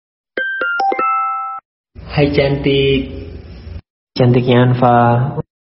Notification icon Nada dering WA sebut nama suara Google
Kategori: Nada dering